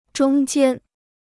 中间 (zhōng jiān): milieu; entre.